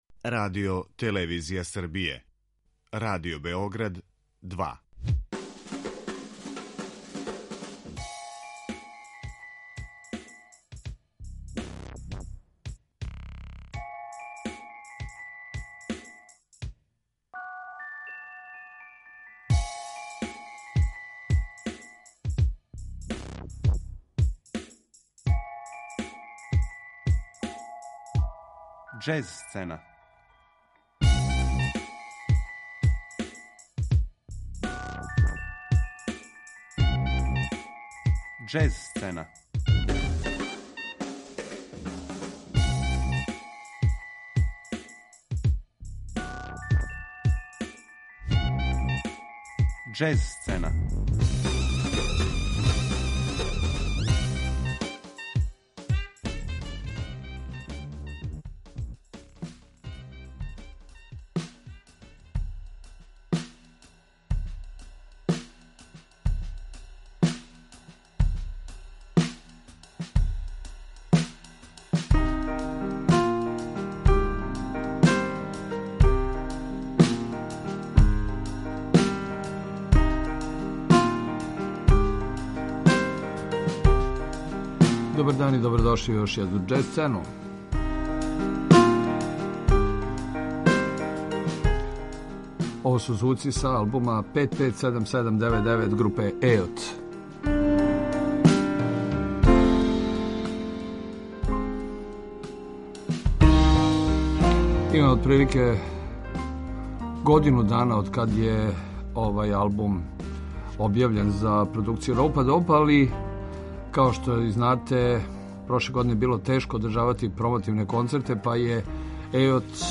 Уз звучне илустрације, у емисији завршавамо причу о јубиларном, 40. међународном фестивалу Jazz sous les pommiers, који је одржан крајем августа у месту Кутанс, у Нормандији (Француска).